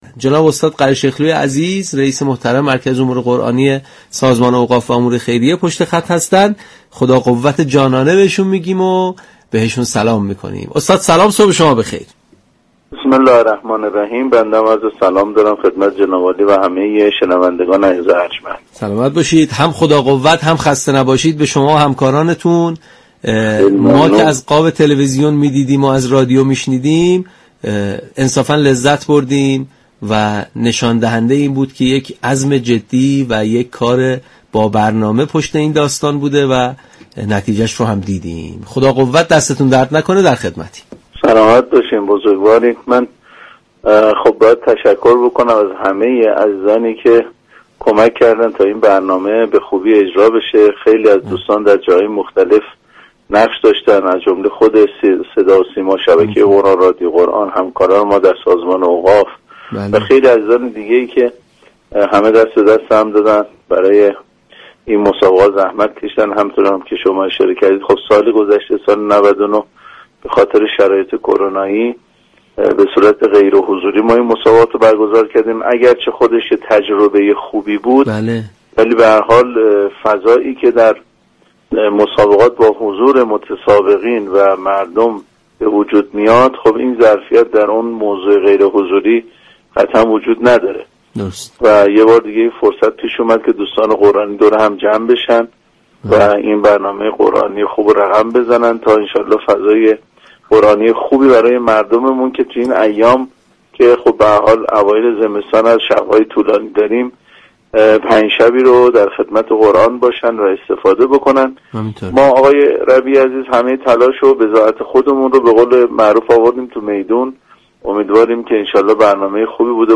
به گزارش پایگاه اطلاع رسانی رادیو قرآن ؛ مهدی قره‌شیخلو، رئیس مركز امور قرآنی سازمان اوقاف و امور خیریه در گفتگو با برنامه تسنیم رادیو قرآن ضمن قدردانی از تمامی دست اندكاران برگزاری چهل و چهارمین دوره مسابقات سراسری قرآن كریم گفت: از امروز برنامه ریزی های لازم برای برگزاری سی و هشتمین دوره مسابقات بین المللی قرآن كریم كه همزمان با عید مبعث در اسفندماه برگزار می شود، آغاز می كنیم.